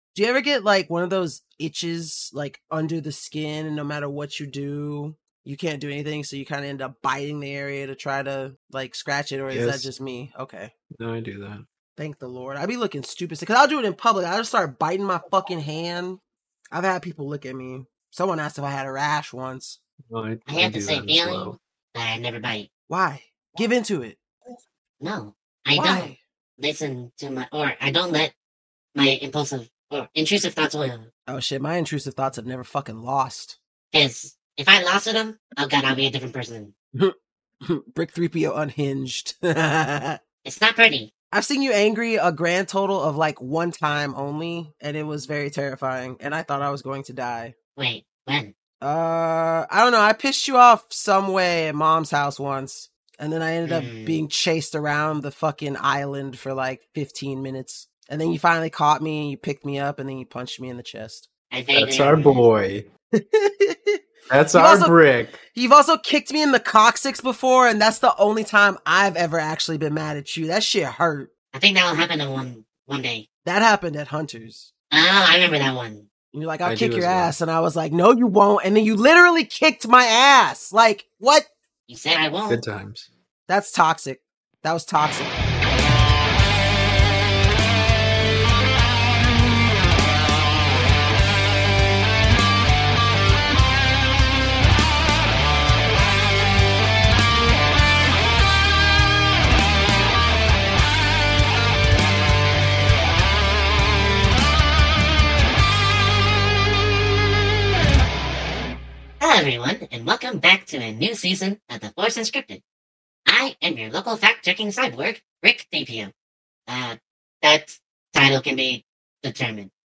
Welcome to The Force Unscripted Podcast, where Star Wars fans share unfiltered discussions, fresh insights, and genuine camaraderie in exploring the galaxy far,...